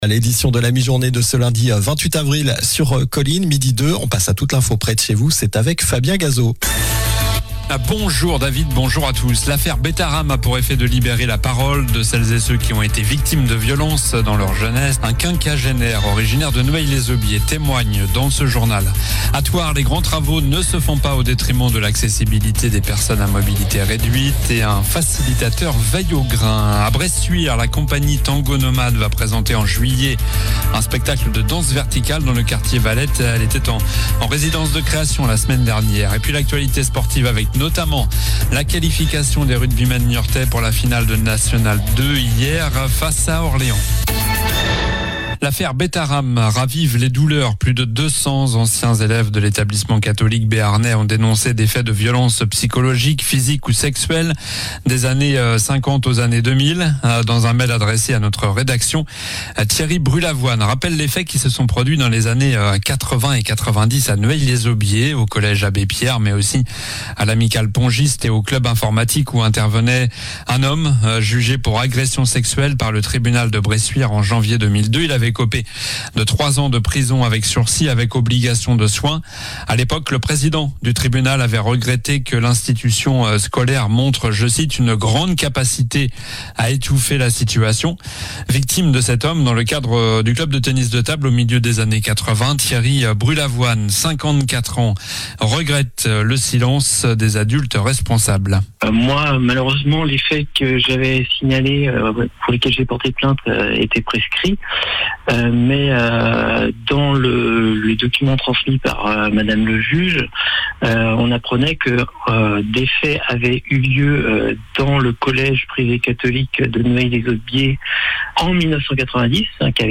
Journal du lundi 28 avril (midi)